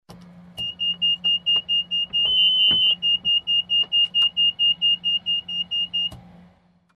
Звуки вертолетов
Звук аварийного оповещения в вертолете